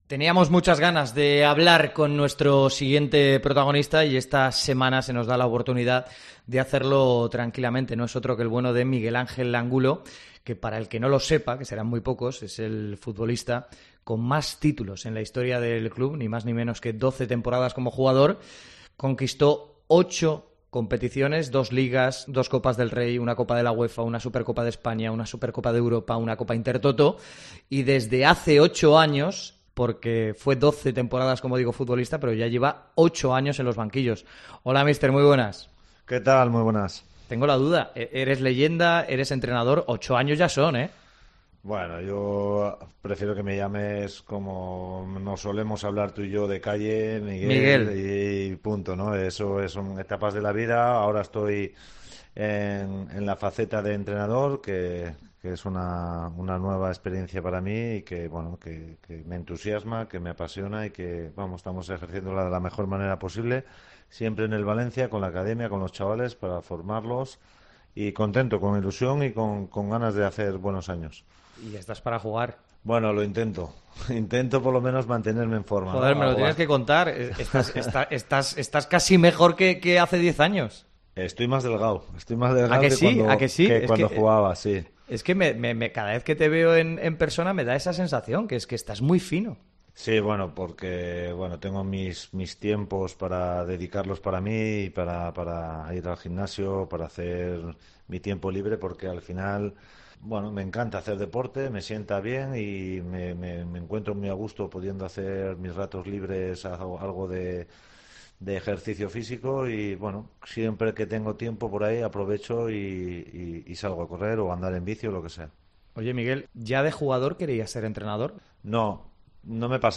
AUDIO. Entrevista a Angulo en Deportes COPE